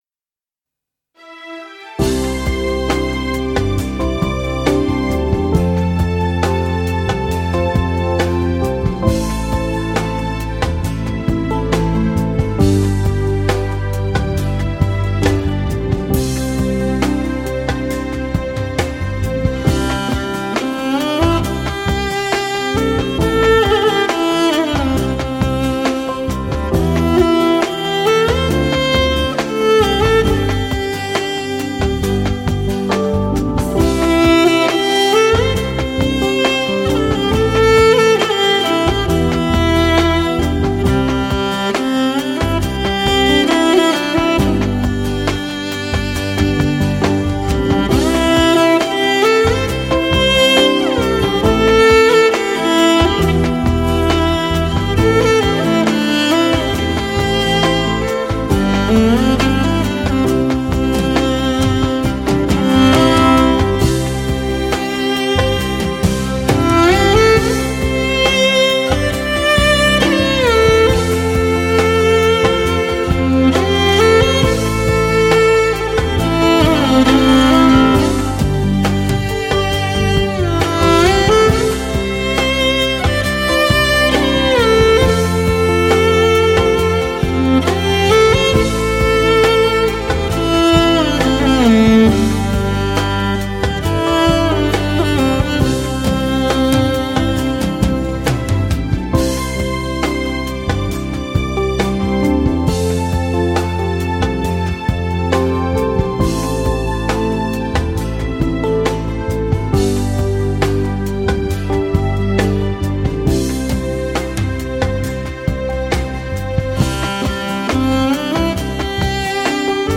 （黑胶）
演奏出浓郁的草原之情